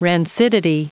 Prononciation du mot rancidity en anglais (fichier audio)
Prononciation du mot : rancidity